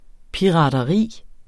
Udtale [ piʁɑːdʌˈʁiˀ ]